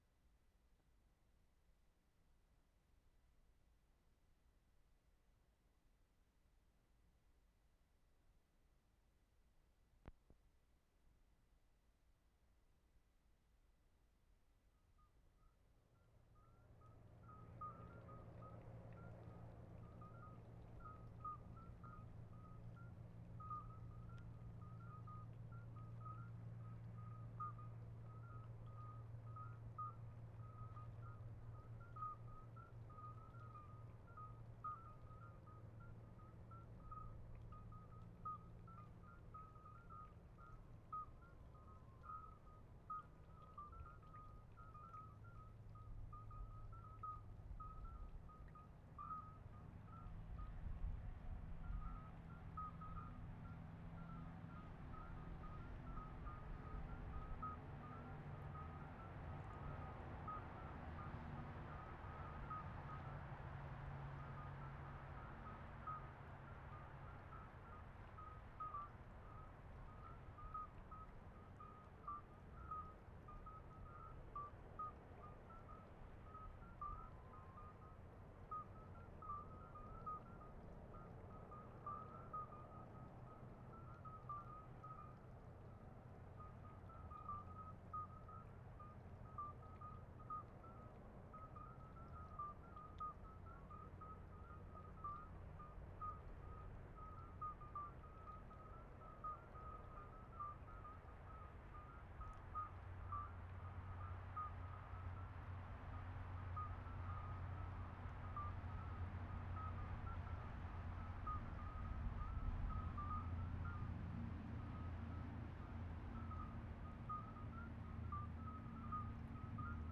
Rennes / Montreuil, France April 18/75
mark * FROGS in same location. [0:15]
2. Attempt at traffic-free recording of frogs, but unfortunately there still was plenty of traffic.